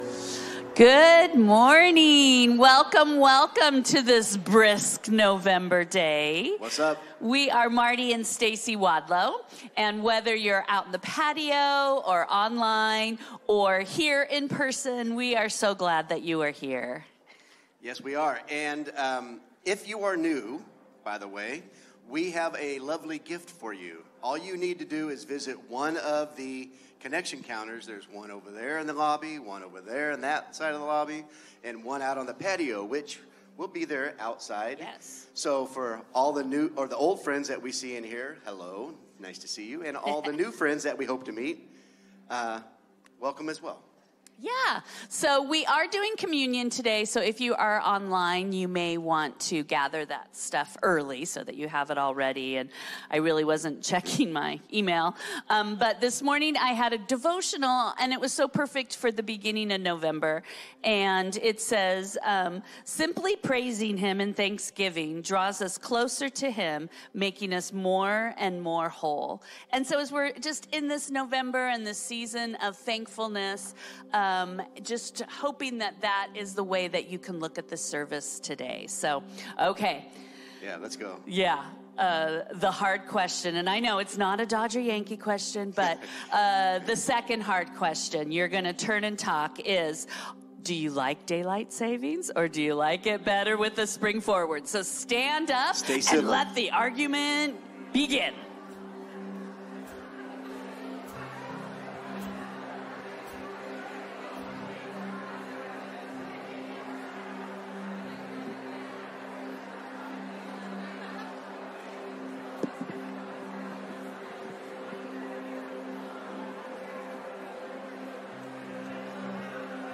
Sermons - N E W L I F E